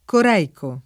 coreico [ kor $ iko ] agg.; pl. m. ‑ci